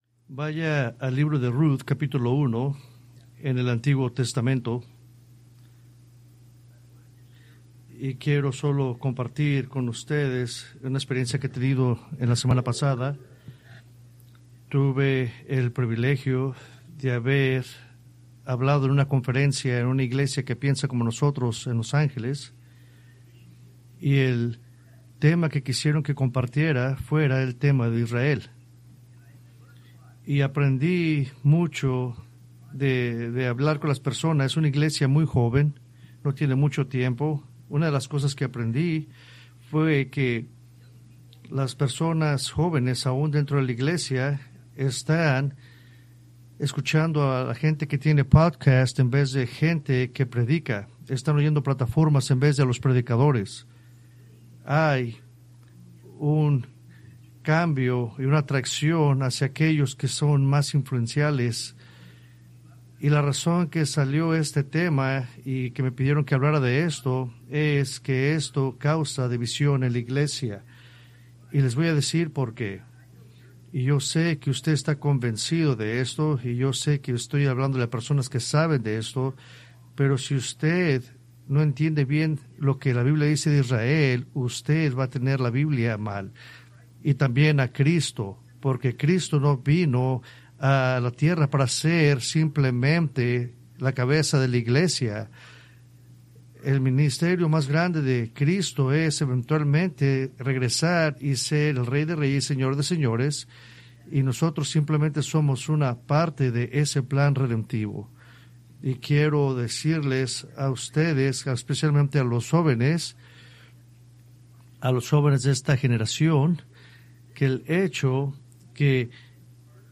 Preached November 2, 2025 from Escrituras seleccionadas